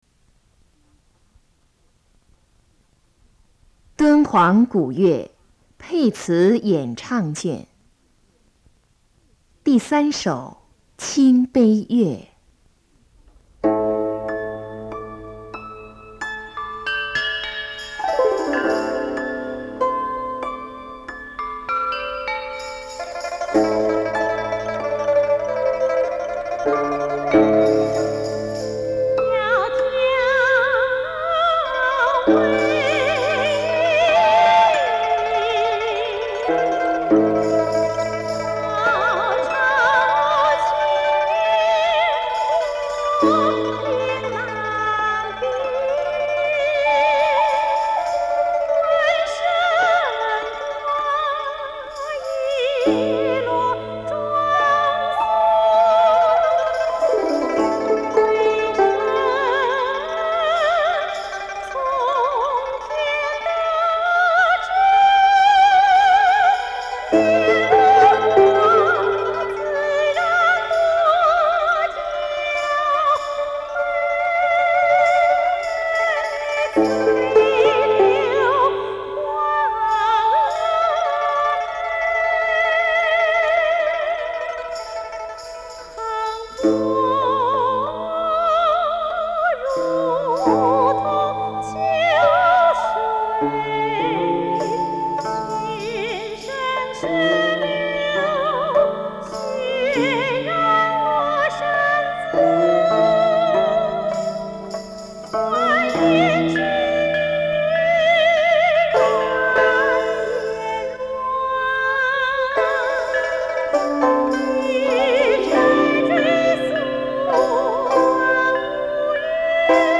音乐类型: 民乐
3TAPE 磁带转录
琵琶独奏